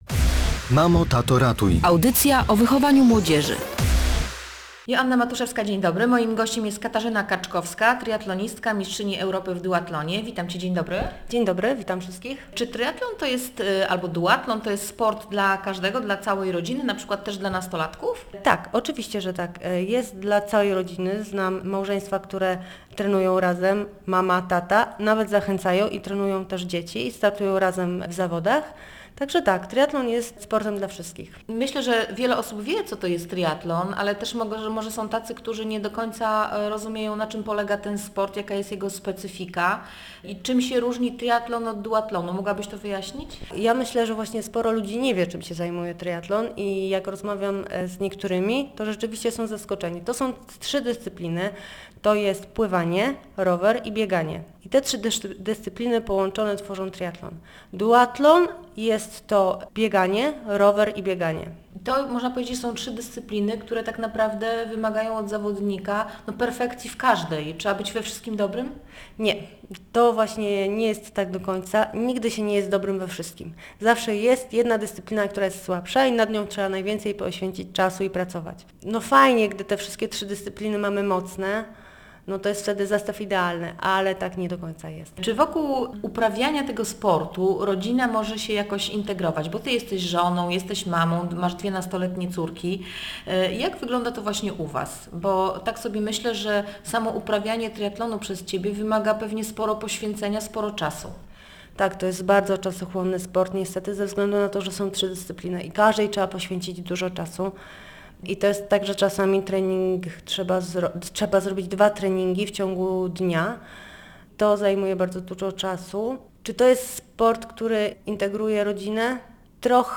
W Radio Gdańsk mówiła, czy to sport dla każdego i jakie cechy charakteru może kształtować u młodzieży.